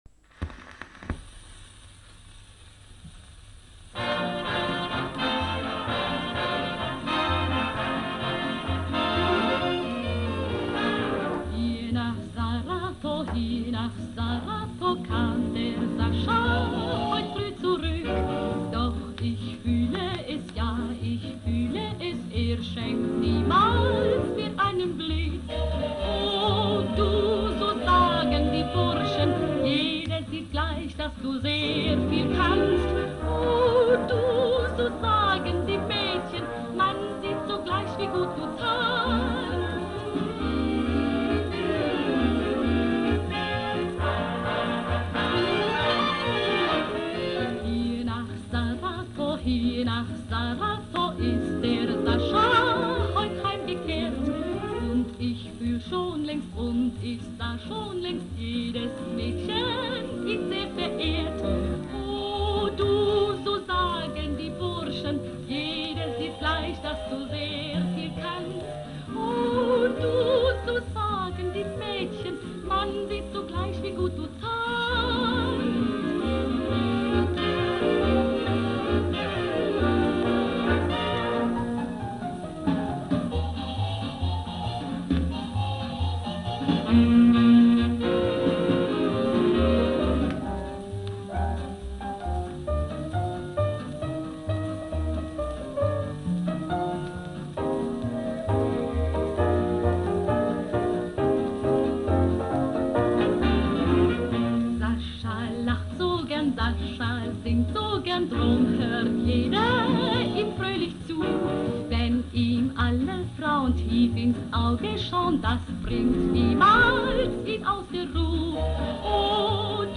фокстрот